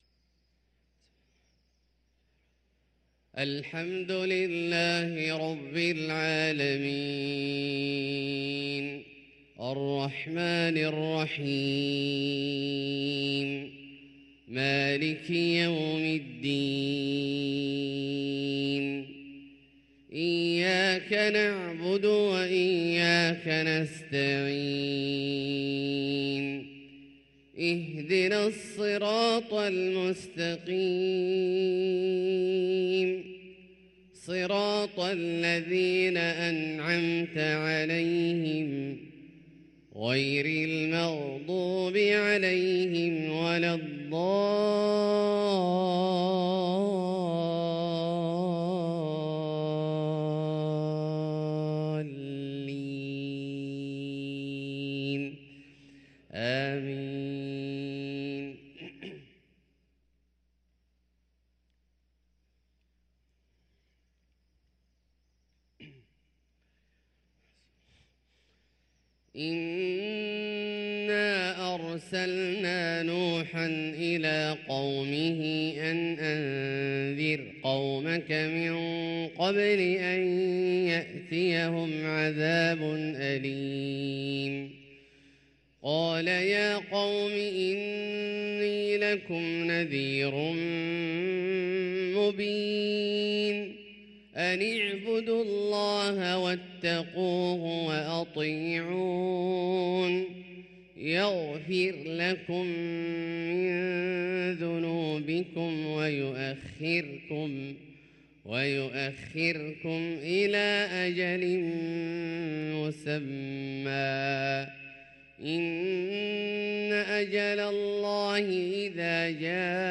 صلاة الفجر للقارئ عبدالله الجهني 11 رجب 1444 هـ
تِلَاوَات الْحَرَمَيْن .